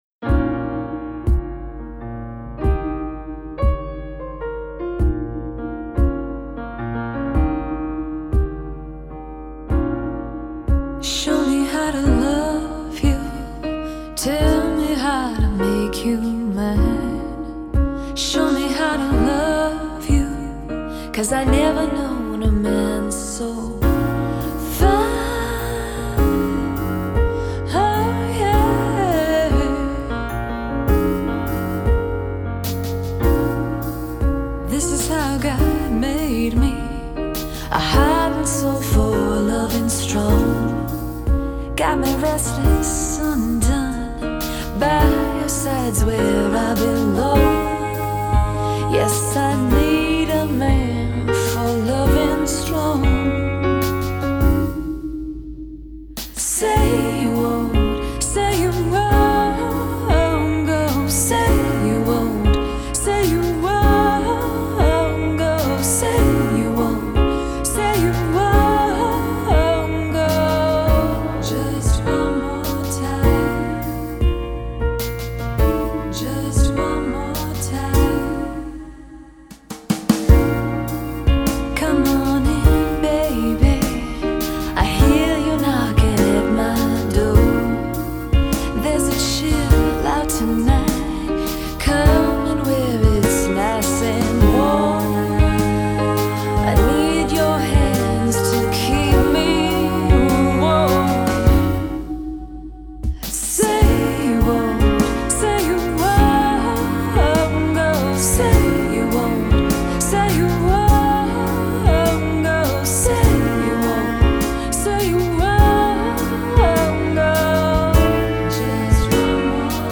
“Show Me” Demo